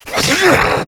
attack_6.wav